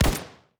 etfx_shoot_pistol02.wav